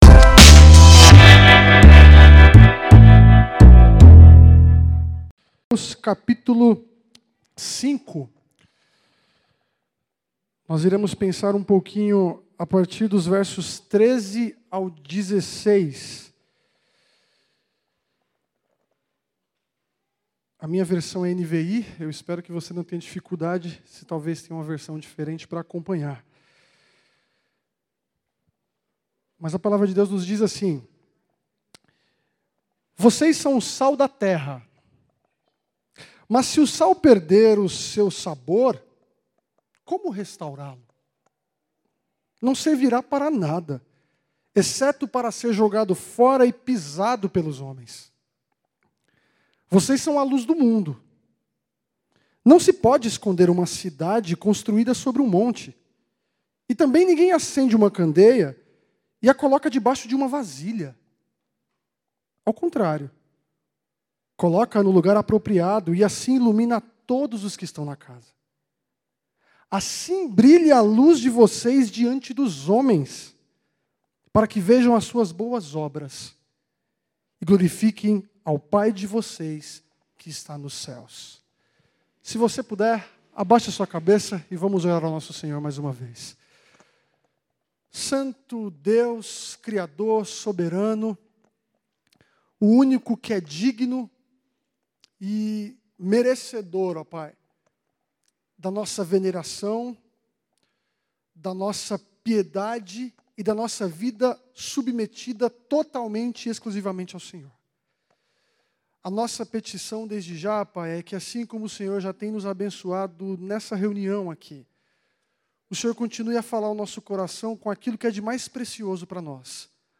Terceira e última mensagem da nossa Conferência Missionária 2021.